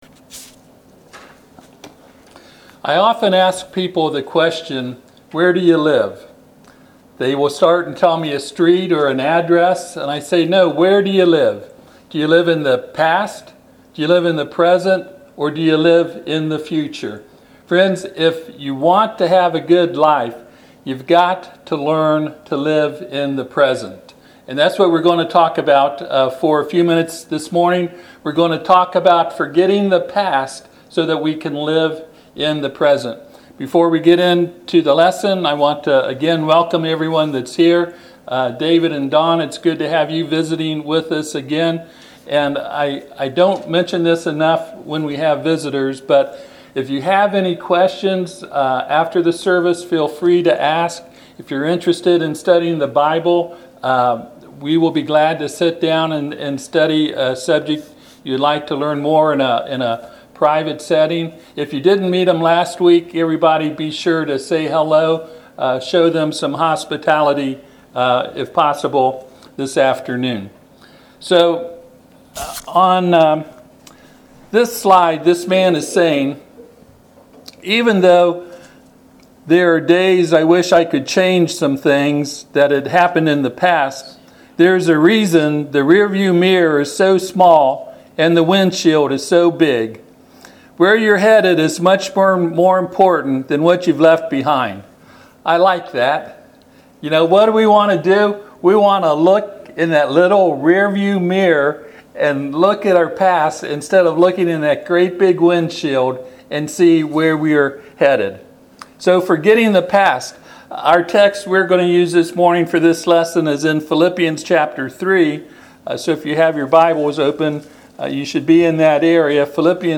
When you to want to have a good life, you must forget your past, and live in TODAY. This sermon is to help you do that.
Philippians 3:12-16 Service Type: Sunday AM I often ask people the question “where do you live?”